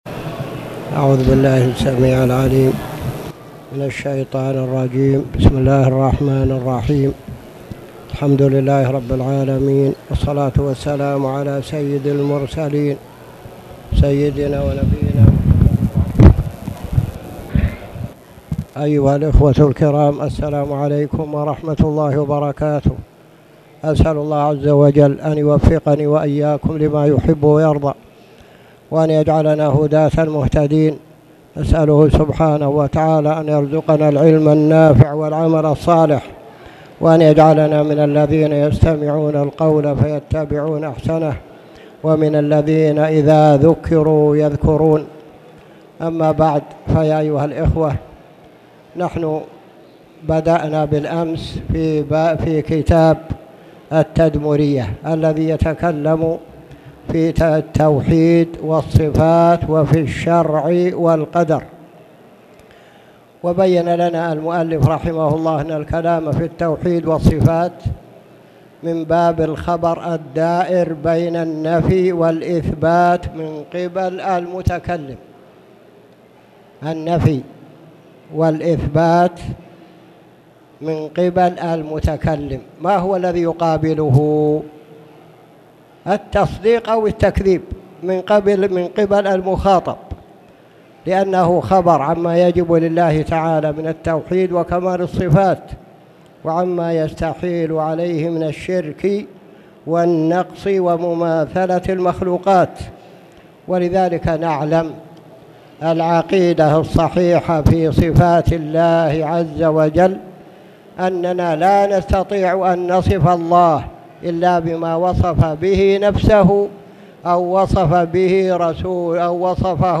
تاريخ النشر ٢ صفر ١٤٣٨ هـ المكان: المسجد الحرام الشيخ